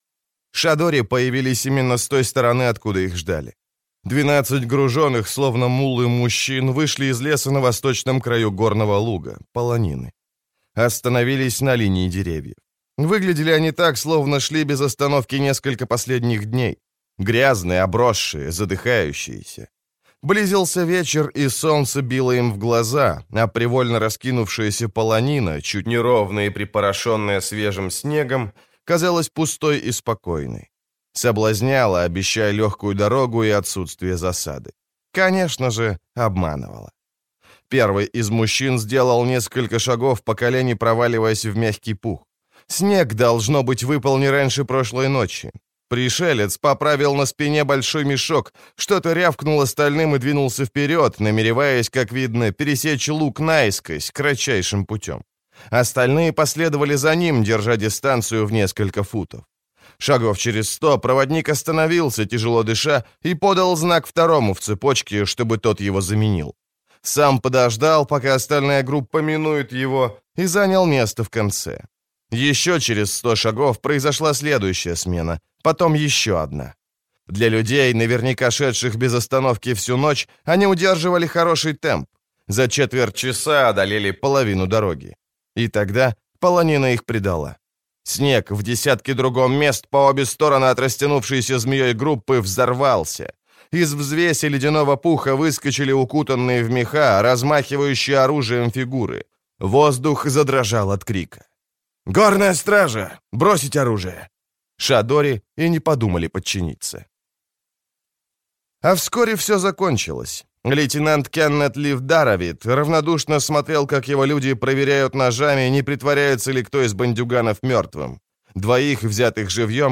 Аудиокнига Сказания Меекханского пограничья. Север – Юг | Библиотека аудиокниг